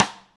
rim shot f.wav